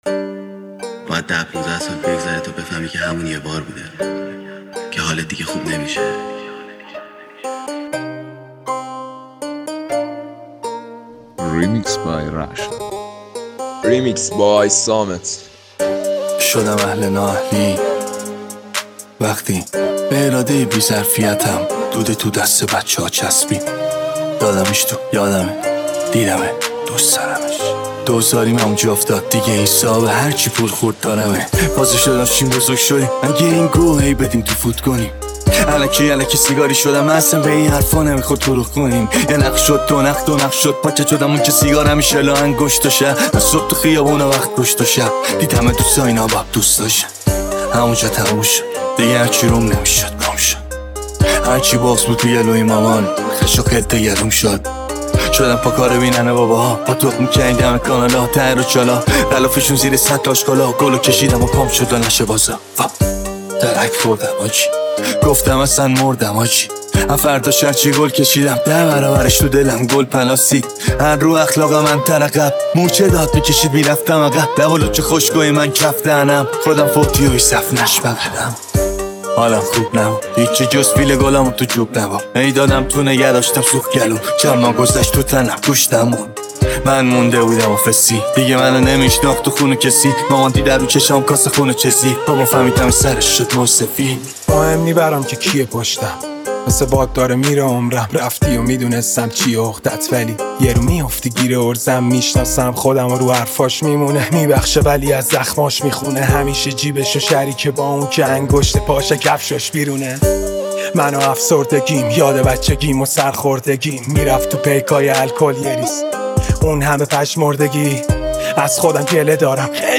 ریمیکس رپ
ریمیکس شاد